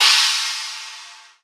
pbs - mexiko dro [ Crash ].wav